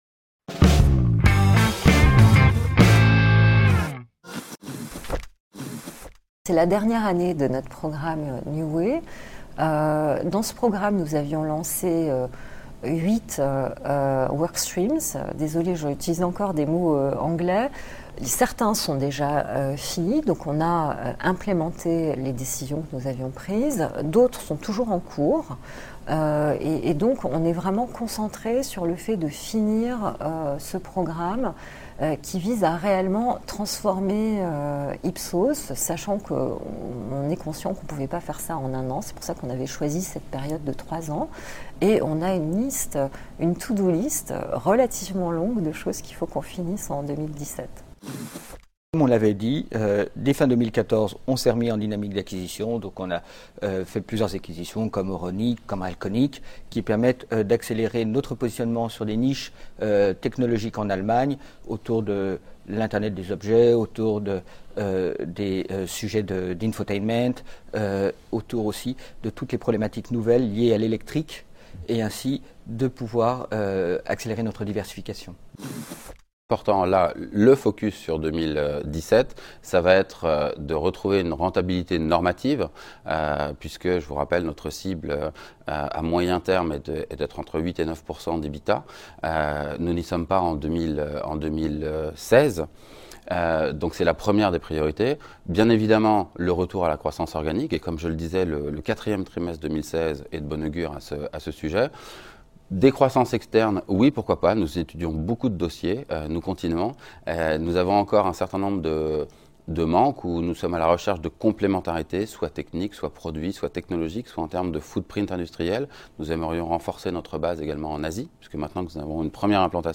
Le BIG ZAPPING +++, dirigeants, entreprises, PME, ETI, Oddo Forum 2017, Lyon